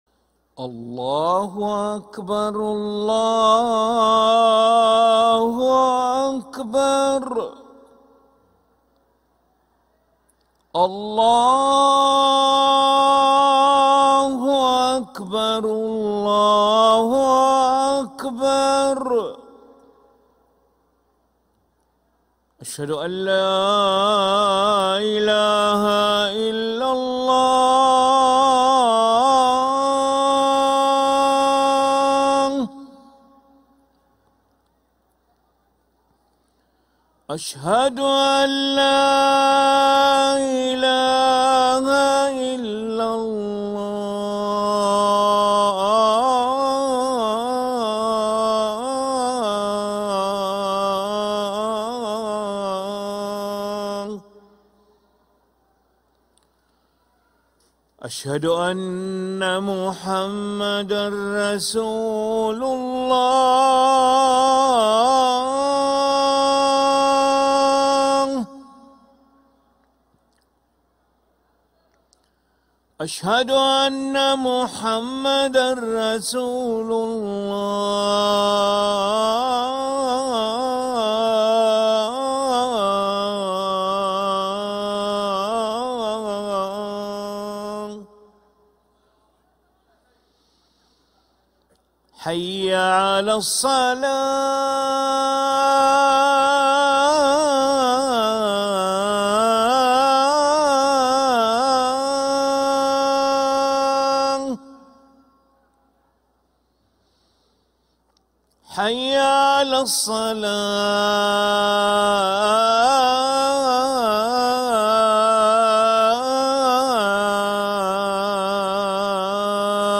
أذان العشاء للمؤذن علي ملا الأحد 19 ربيع الأول 1446هـ > ١٤٤٦ 🕋 > ركن الأذان 🕋 > المزيد - تلاوات الحرمين